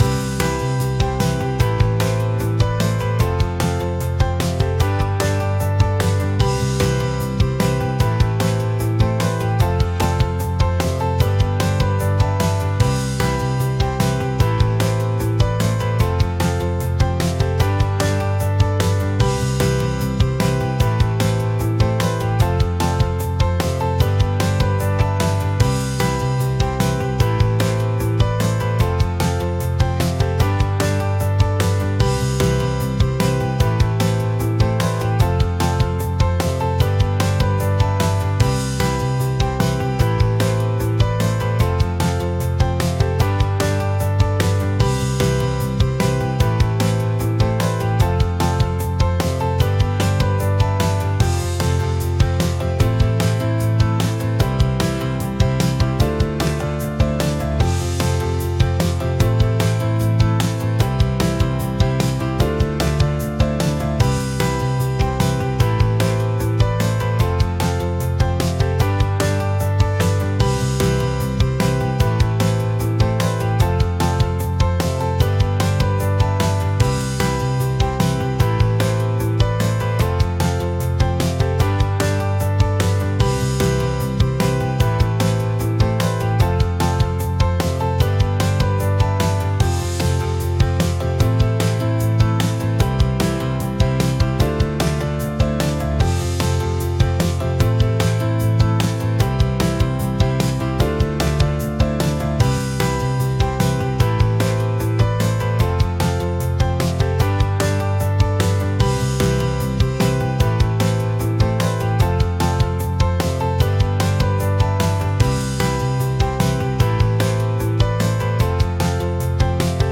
明るい